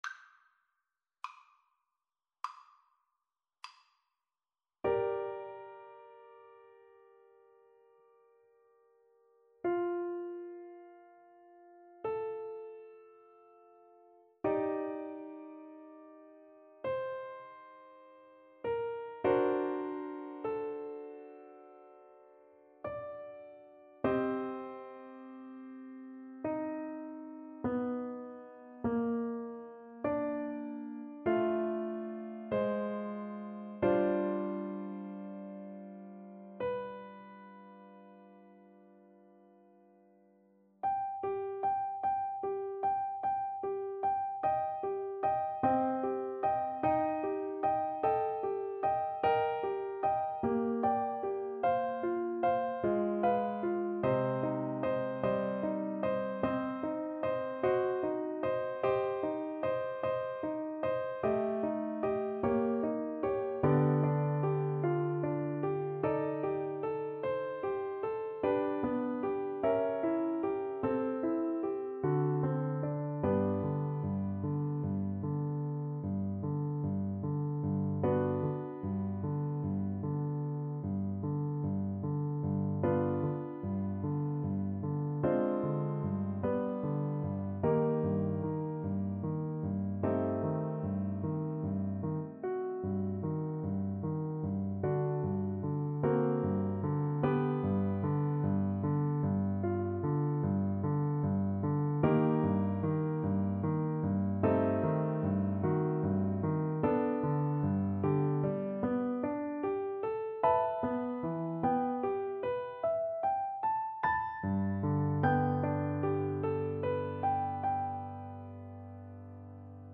Play (or use space bar on your keyboard) Pause Music Playalong - Piano Accompaniment Playalong Band Accompaniment not yet available reset tempo print settings full screen
~ = 100 Lento =50
G minor (Sounding Pitch) (View more G minor Music for Flute )
Classical (View more Classical Flute Music)